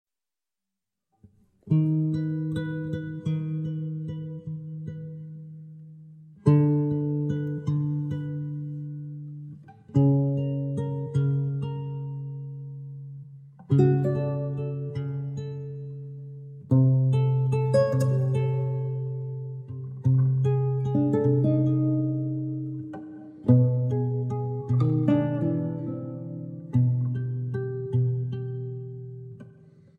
Shimmering Guitar and Lute Duets Composed and Improvised